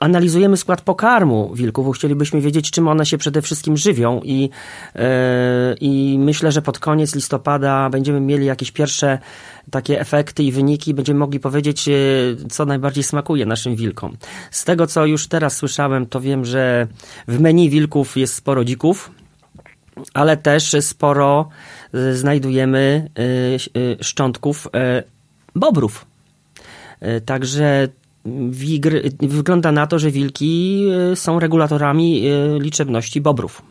Wstępne wyniki wskazują, że wilki najbardziej lubią dziczyznę i bobrzynę. O szczegółach mówił we wtorek (10.10) w Radiu 5